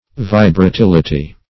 Meaning of vibratility. vibratility synonyms, pronunciation, spelling and more from Free Dictionary.
Search Result for " vibratility" : The Collaborative International Dictionary of English v.0.48: Vibratility \Vi`bra*til"i*ty\, n. [Cf. F. vibratilit['e].] The quality or state of being vibratile; disposition to vibration or oscillation.